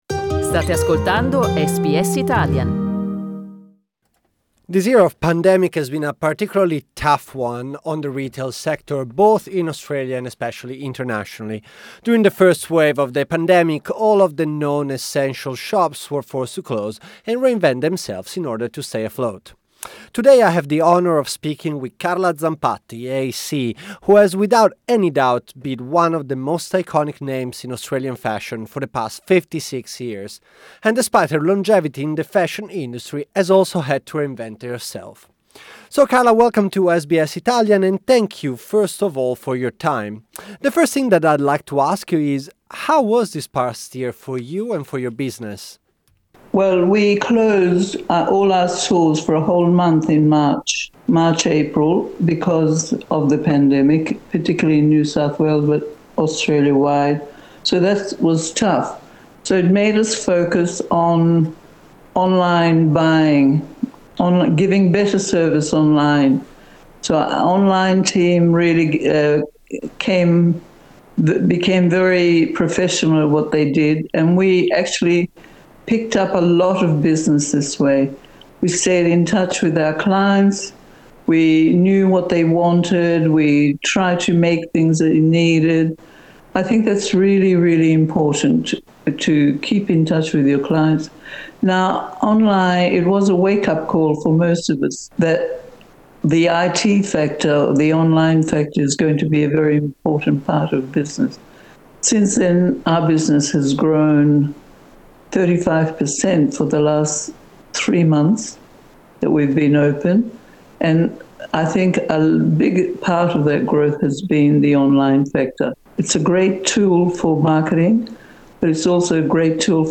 'I learned more in hard times': Carla Zampatti's final interview with SBS Italian